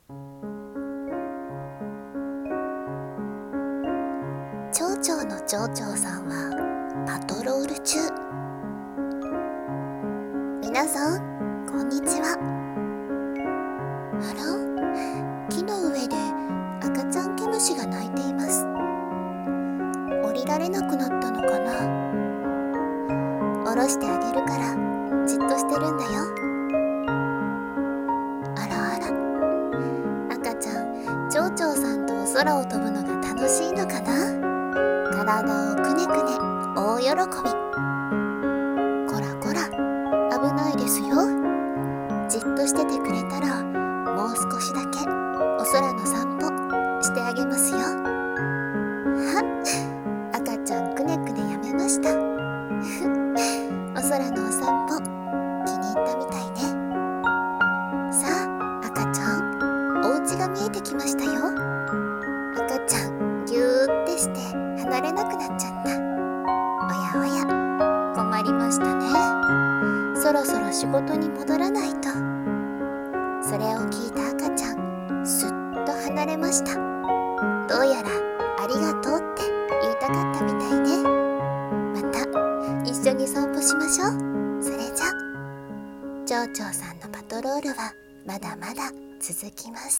『絵本風声劇』蝶々の町長さんのパトロール